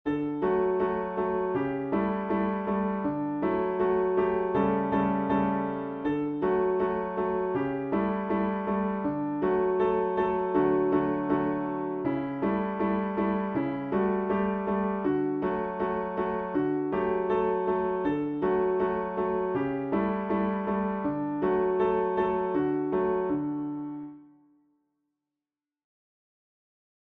AH_BG_Lightly_Row_Musescore_TIN-Piano.mp3